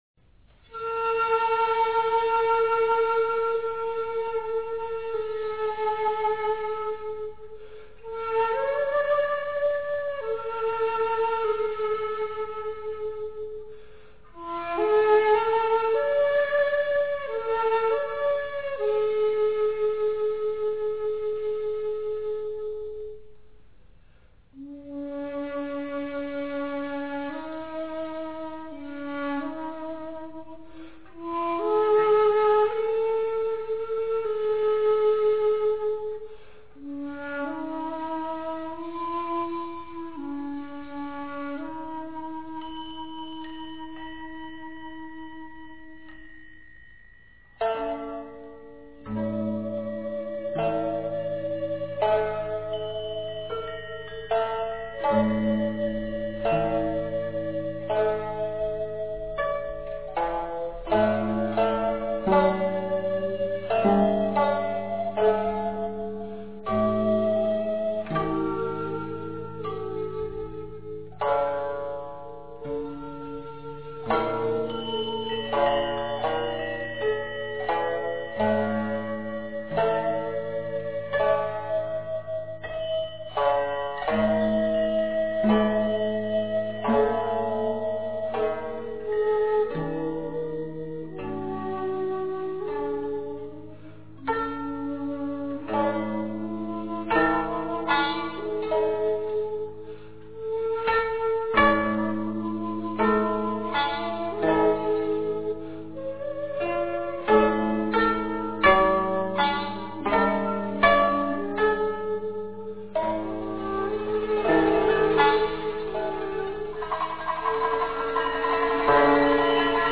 Traditional Japanese music
Taiko drums are a common part of Japanese ceremonies